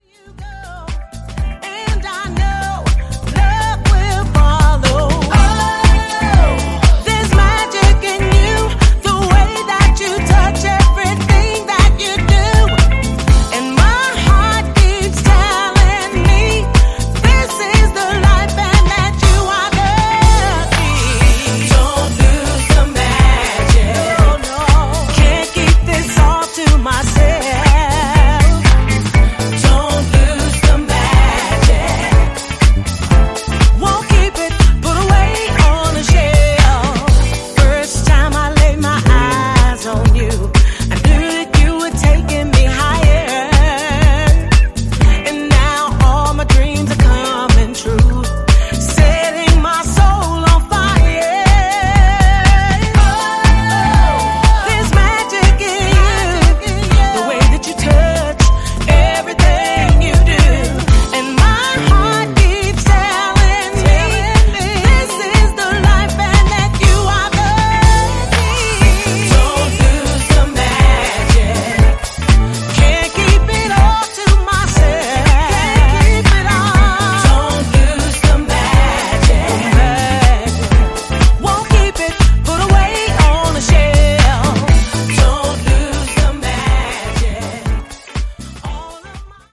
90’s house classic
sublimely soulful update